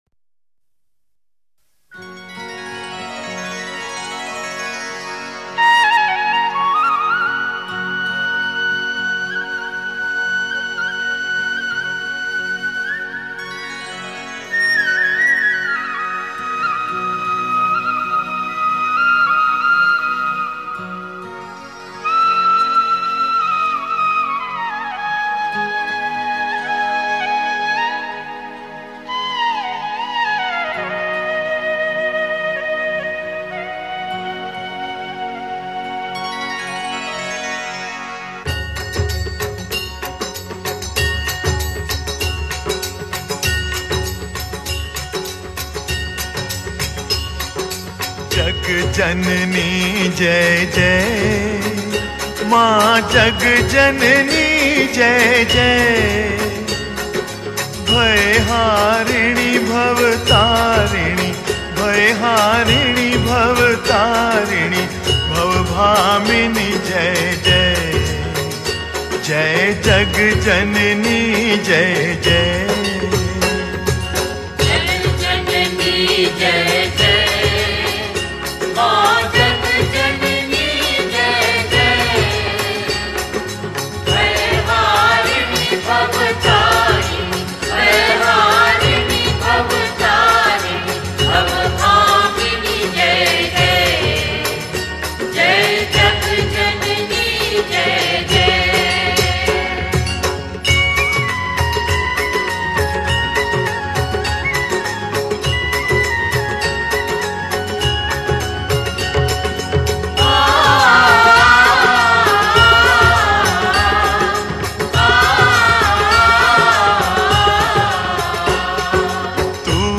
Devotional Single Songs - Bhajans